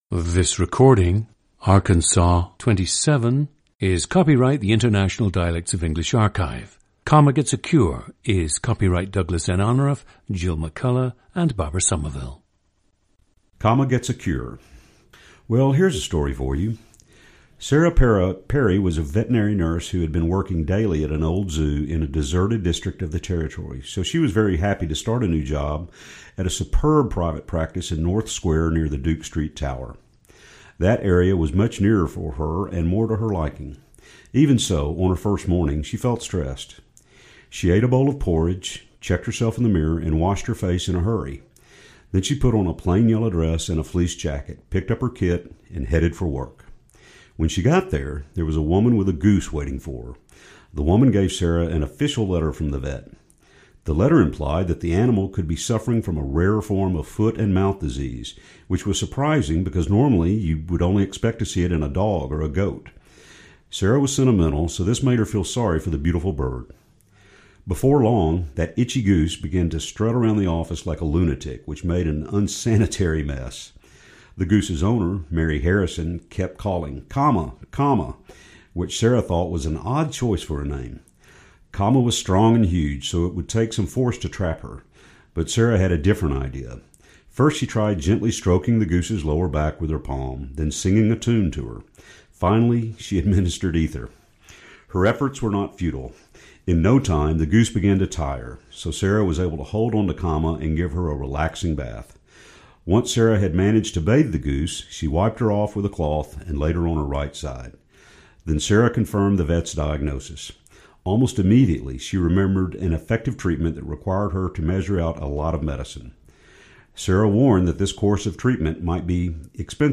GENDER: male
Note the monophthong-ization of /aɪ/ (PRICE) to /a/ (BATH) (liking, implied, wiped, idea, five, invite, drive, eye, I’ve, find, alive, insides, hide, pride).